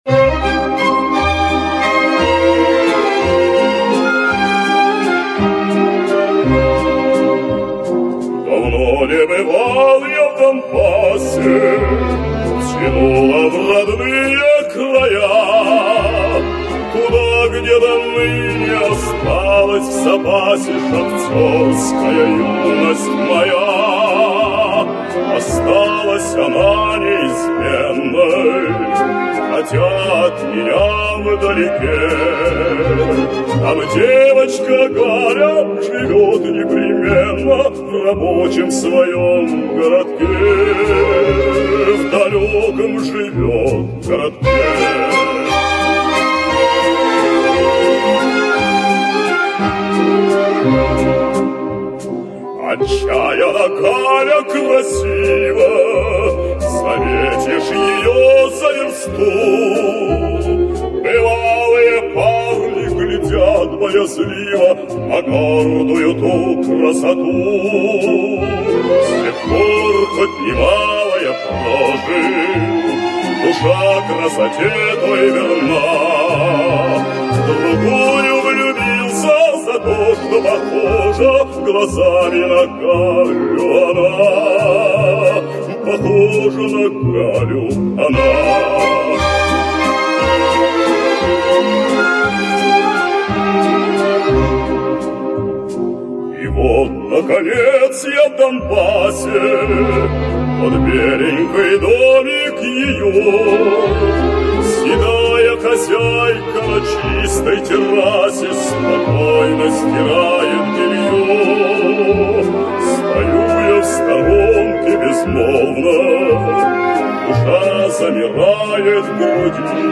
Красивая мелодия!